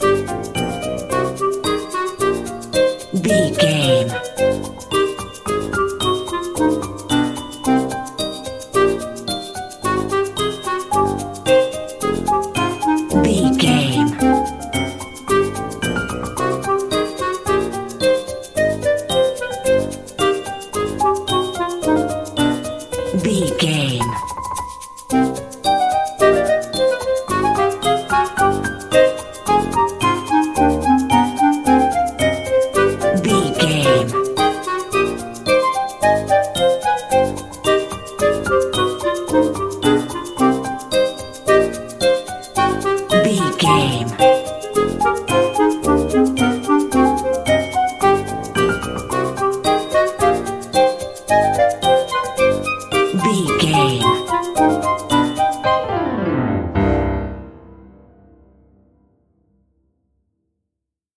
Aeolian/Minor
orchestra
piano
percussion
horns
circus
goofy
comical
cheerful
perky
Light hearted
quirky